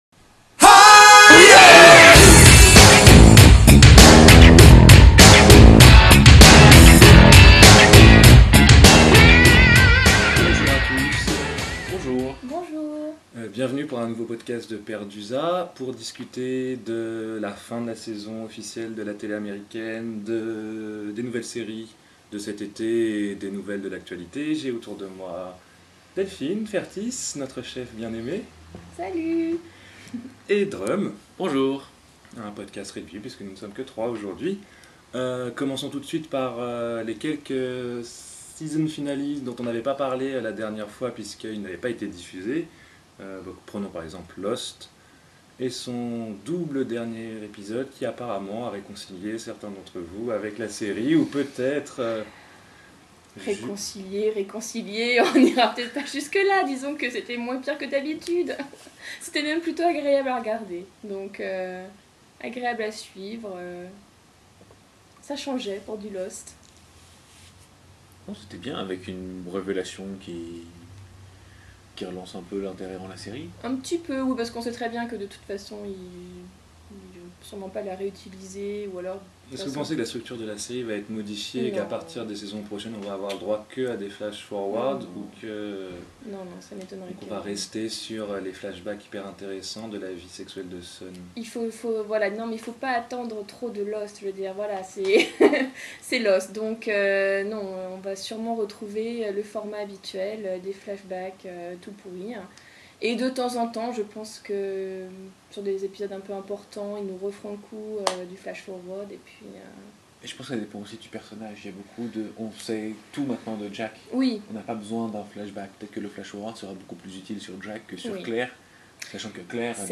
Un podcast intimiste, brut, à peine édité, pour vous donner l’expérience "pErDUSA" dans son expression la plus pure ! Des ricanements, des déclarations infâmes et des moqueries à peine déguisées, mais aussi, parfois, des discussions intéressantes !